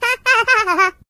project_files/Data/Sounds/voices/Singer/Laugh.ogg
Laugh.ogg